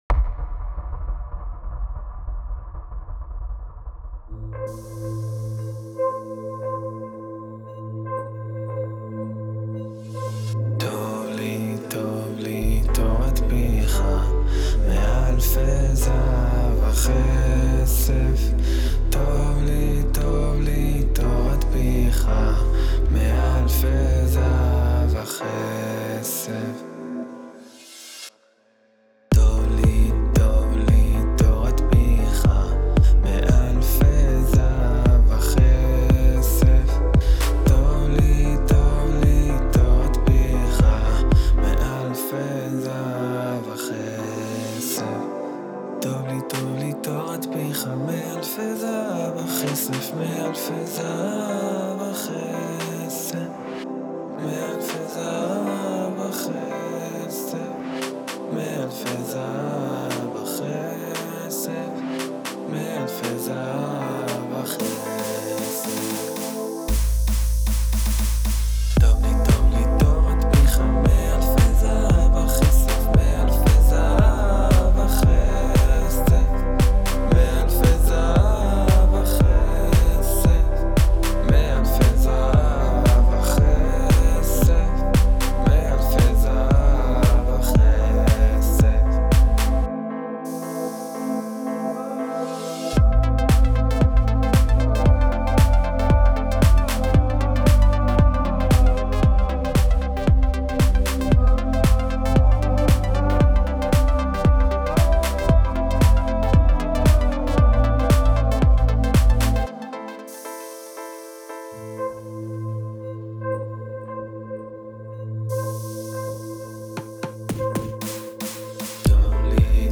“טוב לי” הניגון המוכר בעיבוד חדש…
יפה מאד הליווי עם הפסנתר